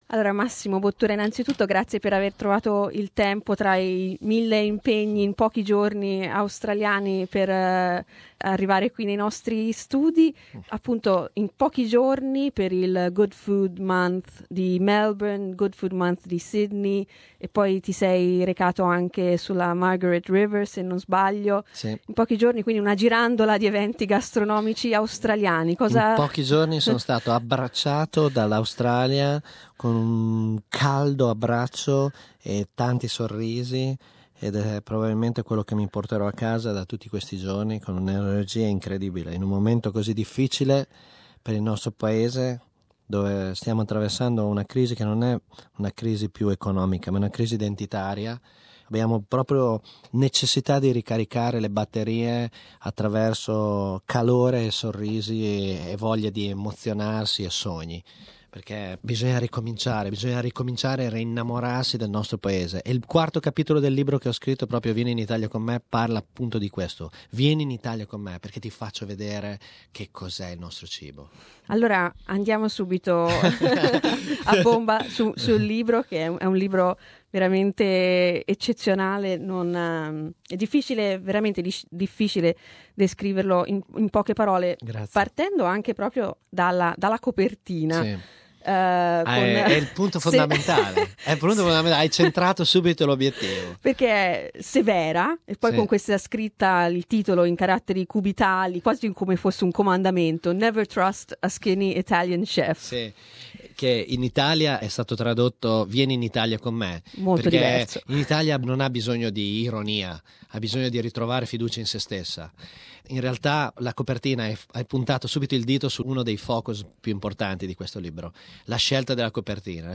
Riproponiamo una nostra intervista a Massimo Bottura, il cui ristorante modenese è stato dichiarato il migliore al mondo. Si tratta del primo locale italiano a salire in vetta alla classifica di The World's Best 50 Restaurants.